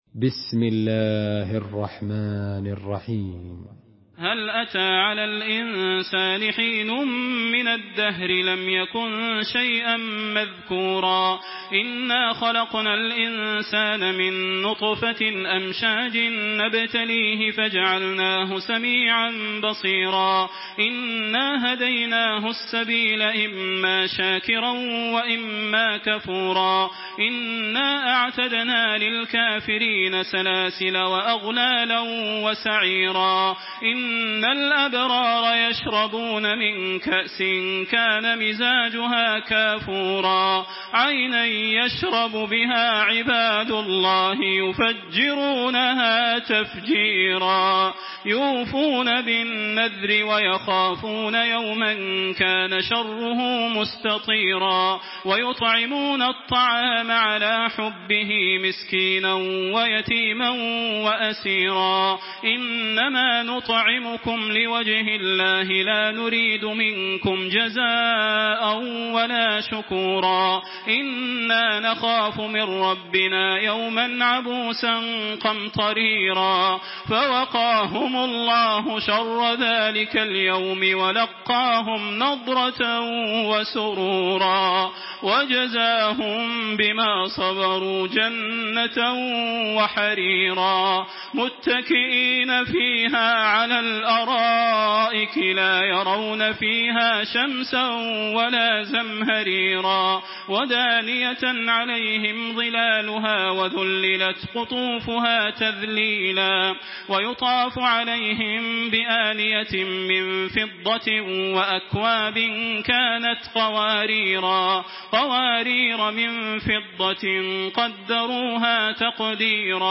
Surah আল-ইনসান MP3 in the Voice of Makkah Taraweeh 1426 in Hafs Narration
Surah আল-ইনসান MP3 by Makkah Taraweeh 1426 in Hafs An Asim narration.
Murattal